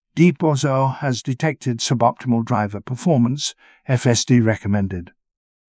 deep-bozo-has-detected.wav